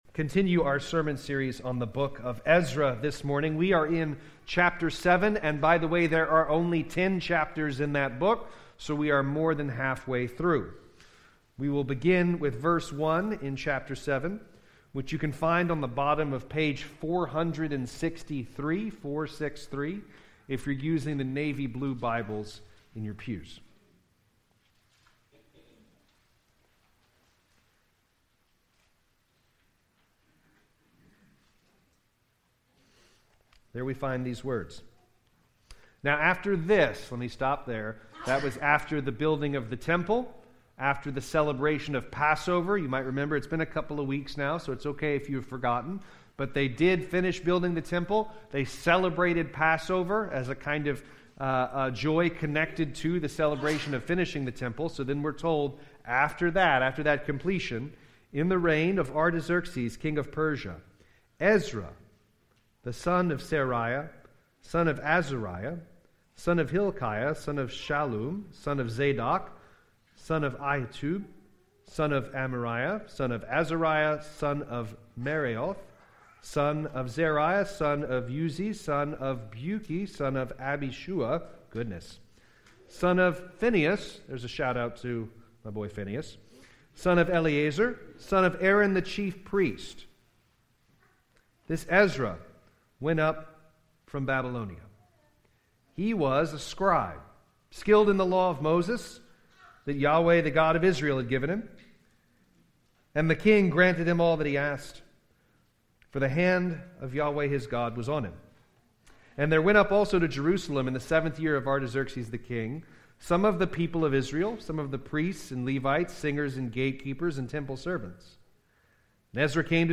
Sermons by Grace Presbyterian Church - Alexandria, LA